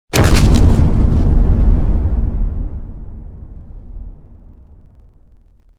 YouHit2.wav